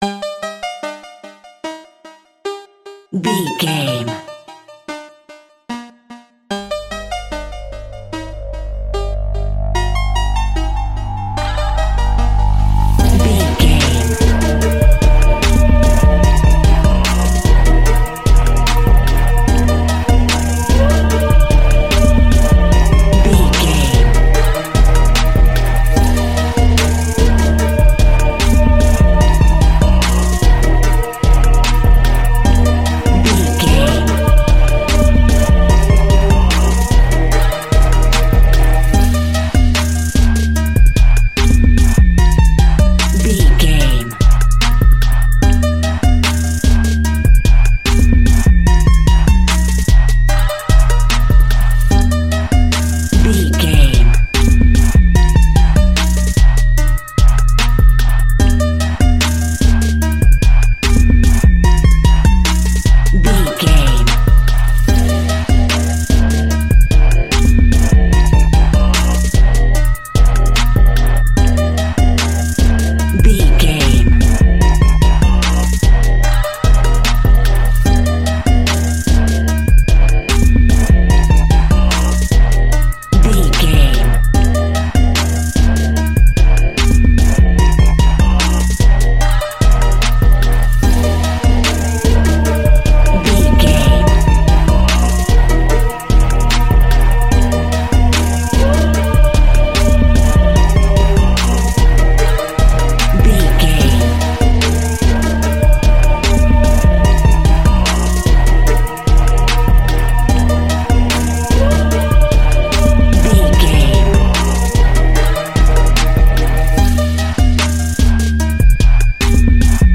Epic / Action
Aeolian/Minor
hip hop
chilled
laid back
groove
hip hop drums
hip hop synths
piano
hip hop pads